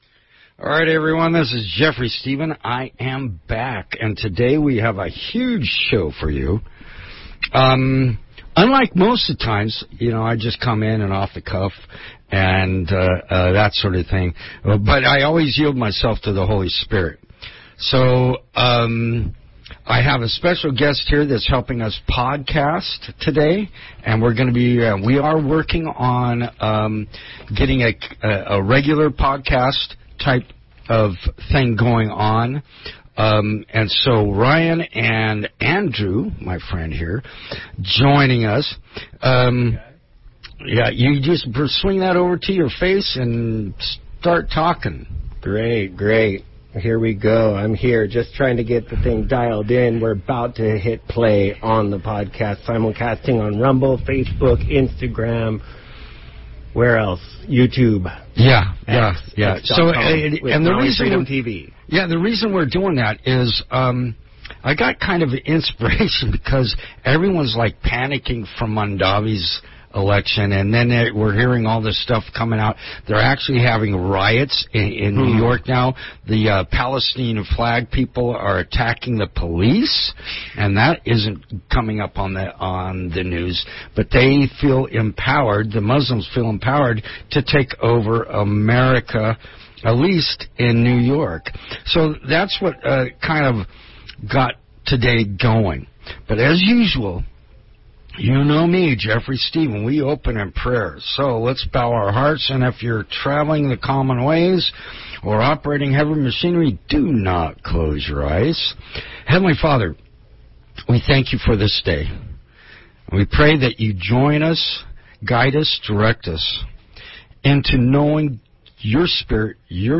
Listen to the replay of the live radio show.